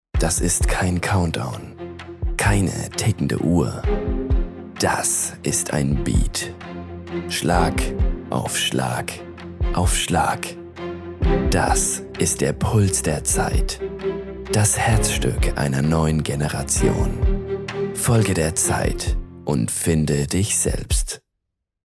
dunkel, sonor, souverän, markant
Jung (18-30)
Bayrisch
Commercial (Werbung), Narrative